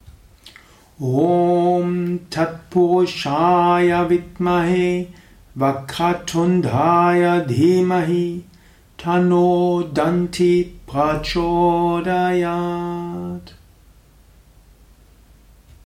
Ganesha Gayatri Rezitation 1x:
Meditative-ruhige Rezitation des Ganesha Gayatri Mantras Om Tatpurush